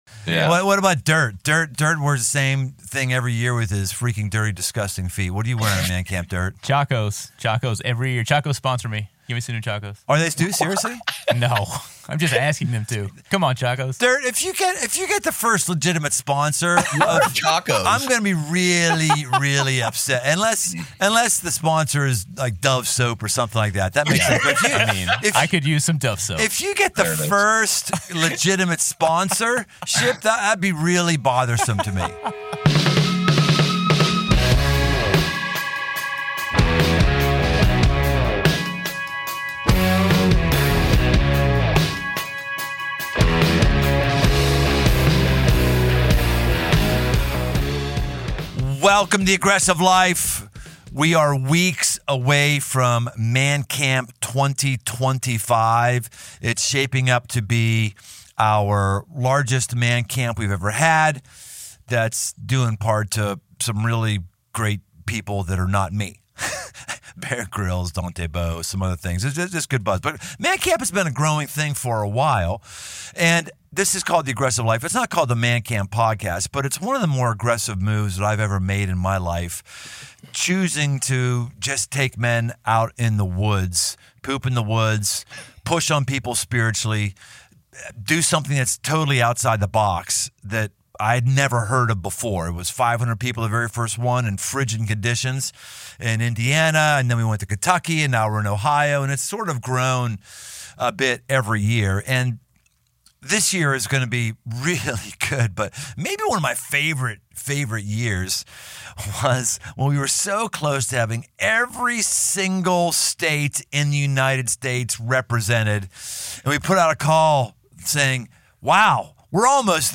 Joining us from their studio in New Hampshire
This aggressive conversation will get you off the couch and out the door.